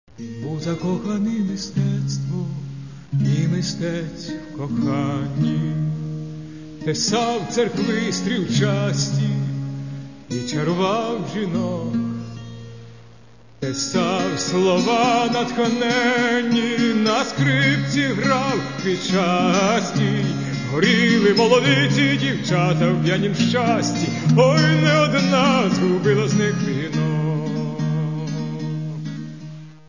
Каталог -> Народная -> Бандура, кобза